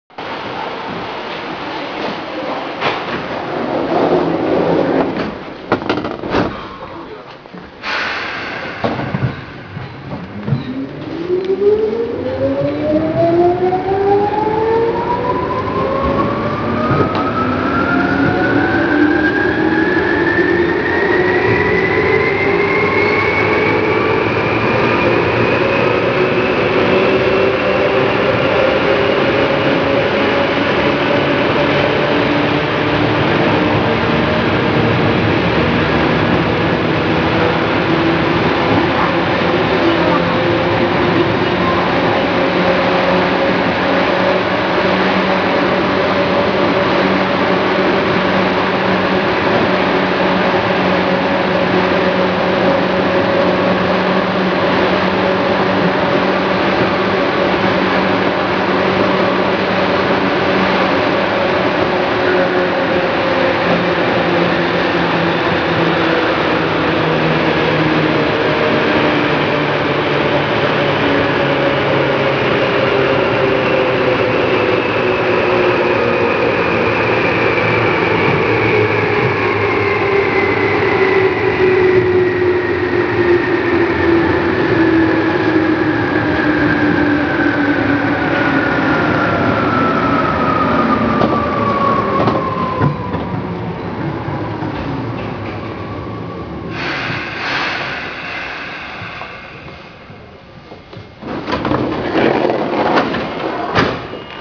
・8590系走行音
【田園都市線】三軒茶屋→池尻大橋（1分44秒：568KB）
とはいえ、走行音が大きいので地下区間ではイマイチよく聞こえないのですが。